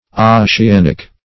Search Result for " ossianic" : The Collaborative International Dictionary of English v.0.48: Ossianic \Os`si*an"ic\, prop. a. Of or pertaining to, or characteristic of, Ossian, a legendary Erse or Celtic bard.
ossianic.mp3